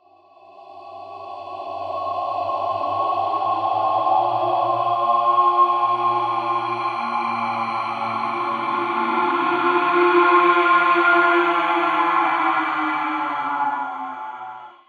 Efecto musical de terror
terror
Sonidos: Especiales
Sonidos: Música